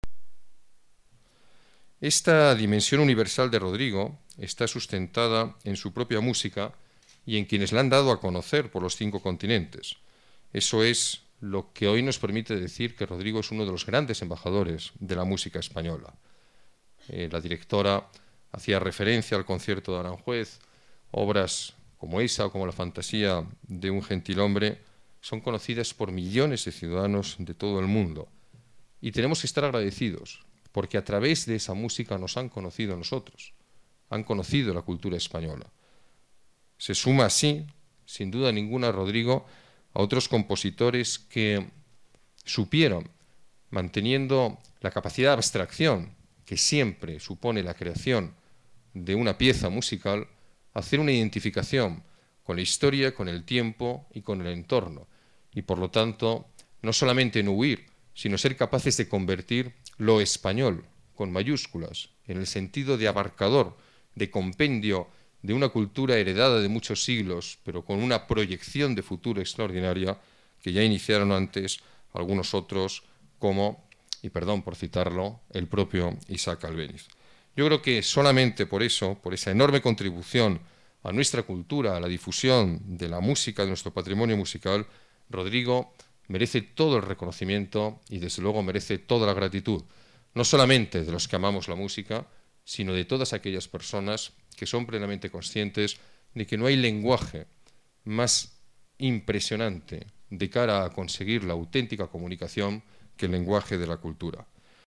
Nueva ventana:Declaraciones del alcalde, Alberto Ruiz-Gallardón.